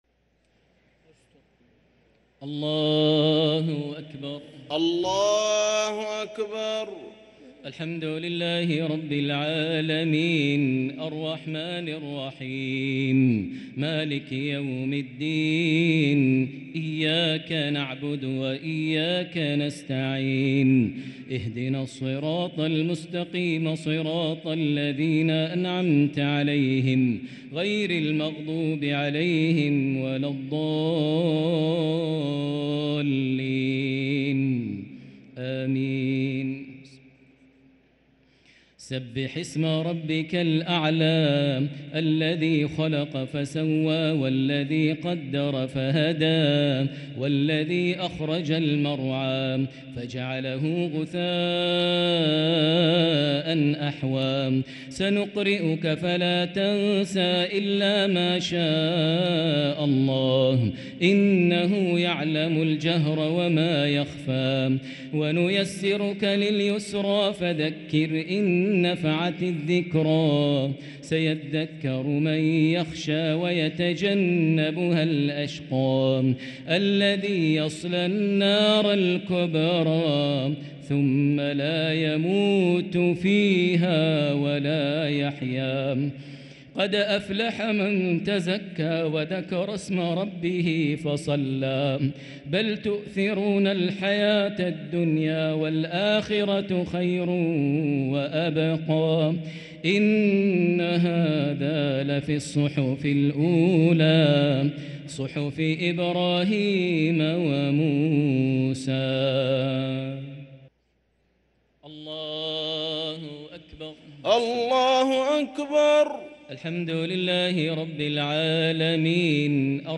صلاة التراويح ليلة 5 رمضان 1444 للقارئ ماهر المعيقلي - التسليمتان الأخيرتان صلاة التراويح